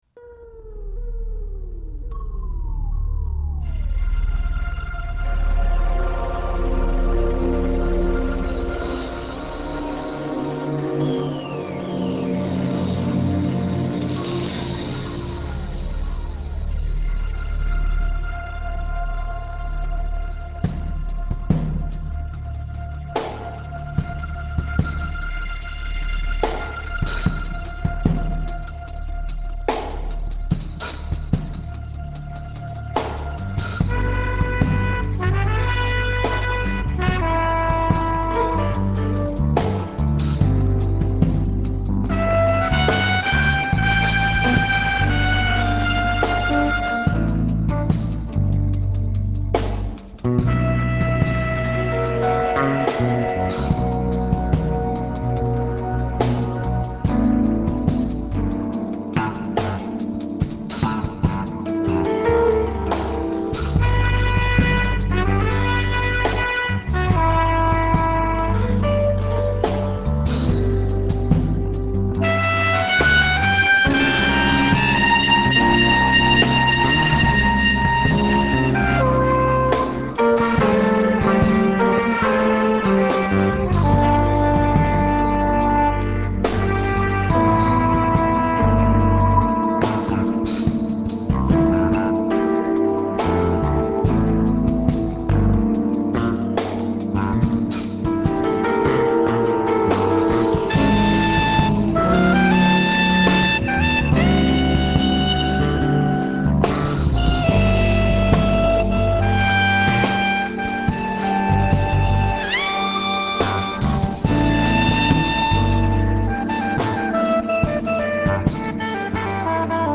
soprano saxophone
trumpet
piano
bass
drums